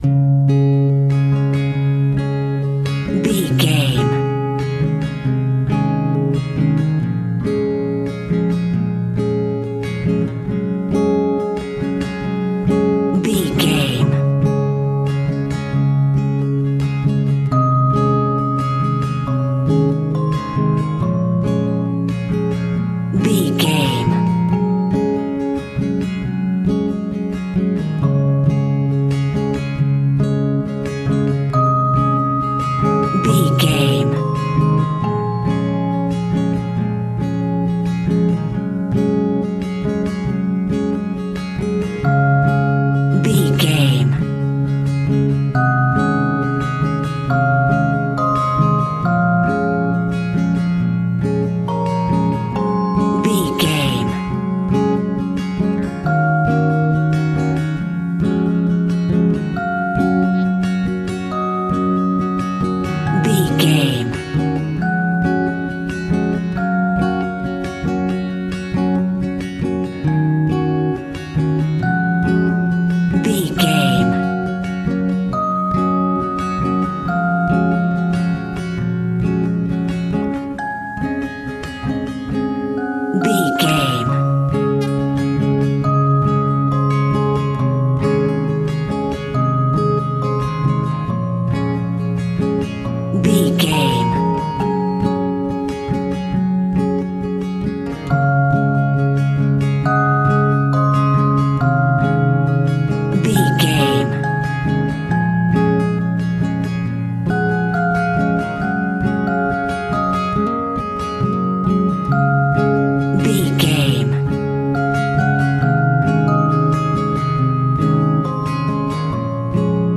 Ionian/Major
C#
Slow
pop
pop rock
indie pop
energetic
uplifting
acoustic guitar
drums
bass gutiar
piano